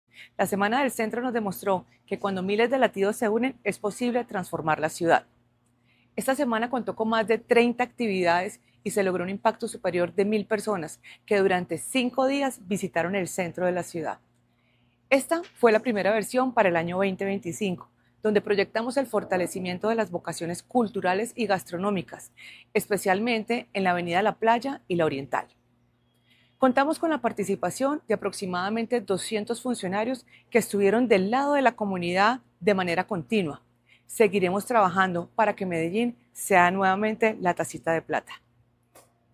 Declaraciones-gerente-del-Centro-y-Territorios-Estrategicos-Juliana-Coral-1.mp3